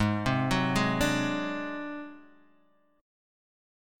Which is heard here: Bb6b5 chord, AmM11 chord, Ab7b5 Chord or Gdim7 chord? Ab7b5 Chord